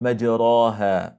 det för inte uttalas som alif (ا)